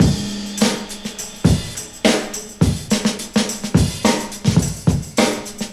• 94 Bpm High Quality Rock Breakbeat C# Key.wav
Free drum loop sample - kick tuned to the C# note. Loudest frequency: 1772Hz
94-bpm-high-quality-rock-breakbeat-c-sharp-key-1N5.wav